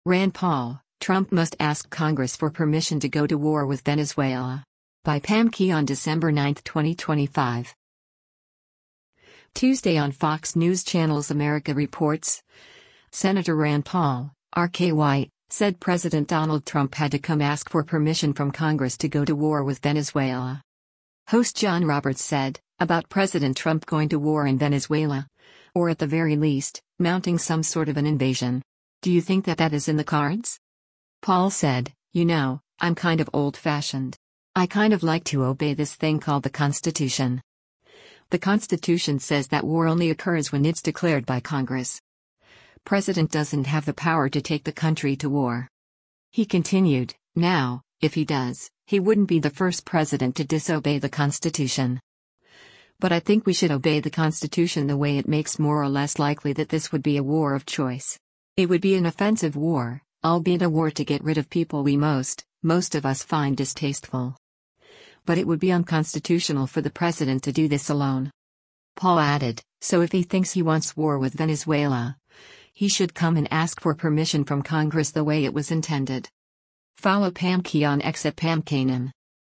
Tuesday on Fox News Channel’s “America Reports,” Sen. Rand Paul (R-KY) said President Donald Trump had to come “ask for permission from Congress” to go to war with Venezuela.